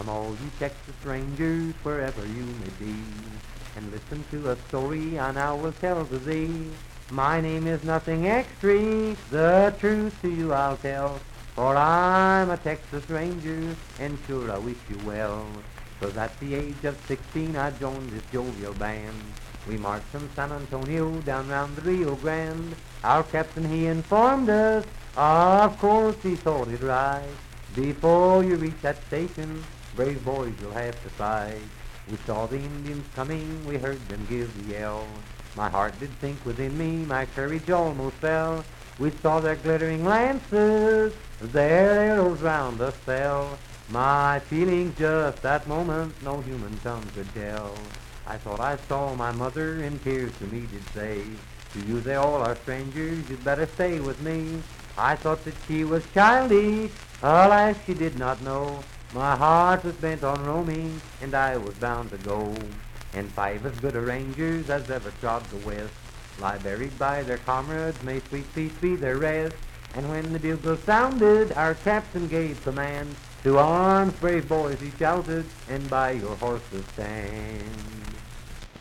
Unaccompanied vocal music
Verse-refrain 4(4).
Voice (sung)
Wood County (W. Va.), Parkersburg (W. Va.)